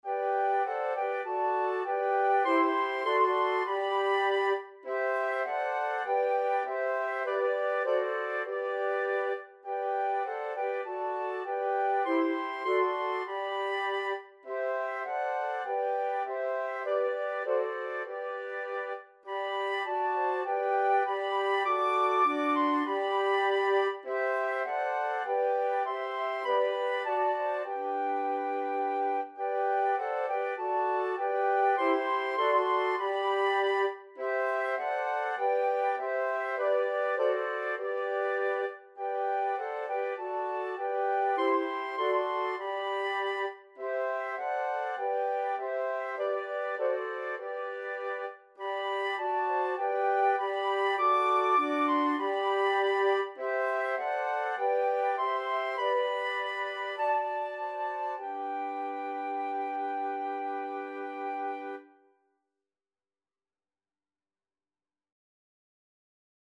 Christian church hymn
flute ensemble